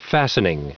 Prononciation du mot fastening en anglais (fichier audio)
Prononciation du mot : fastening